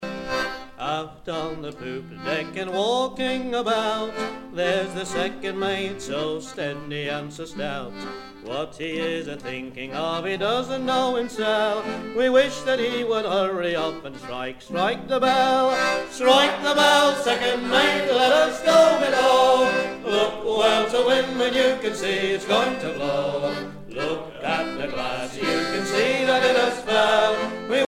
maritimes
Pièce musicale éditée